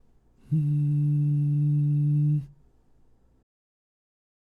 次に、巨人の喉頭の状態のまま、グー/チョキ/パーそれぞれの声で「ん」と発声して下さい。
※喉頭は巨人状態のパーの声(ん)